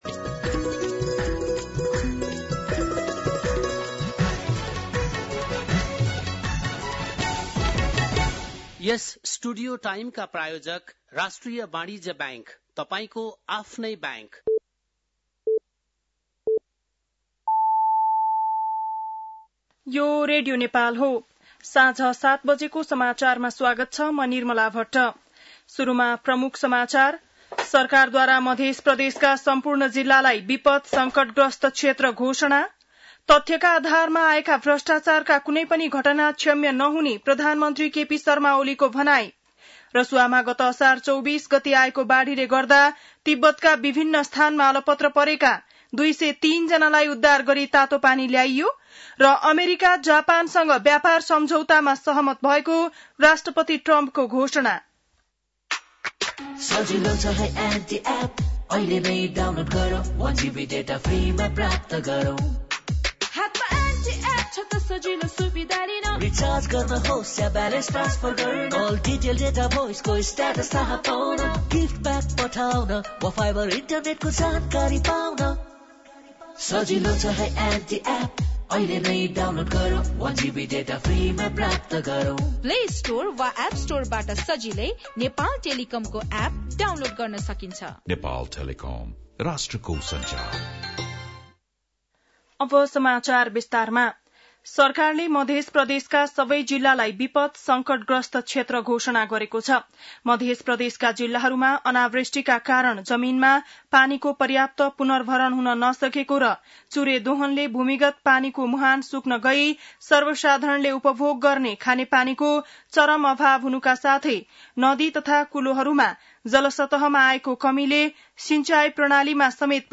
बेलुकी ७ बजेको नेपाली समाचार : ७ साउन , २०८२
7-pm-nepali-news-4-07.mp3